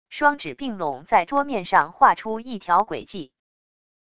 双指并拢在桌面上划出一条轨迹wav音频